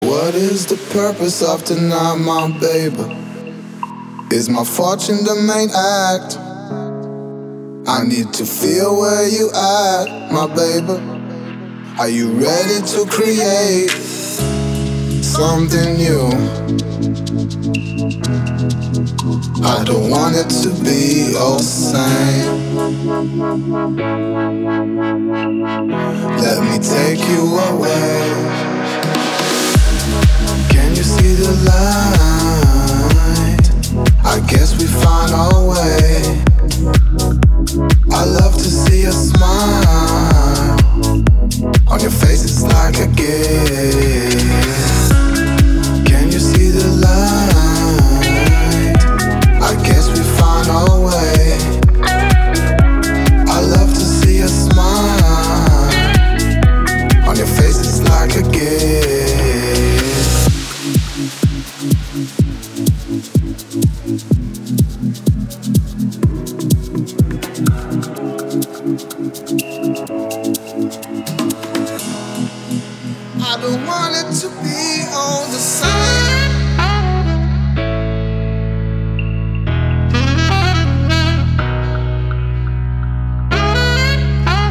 • Качество: 320, Stereo
мелодичные
приятные
Стиль: deep house